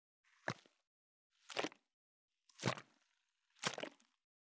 362,500のペットボトル,ペットボトル振る,ワインボトルを振る,水の音,ジュースを振る,シャカシャカ,カシャカシャ,チャプチャプ,ポチャポチャ,
ペットボトル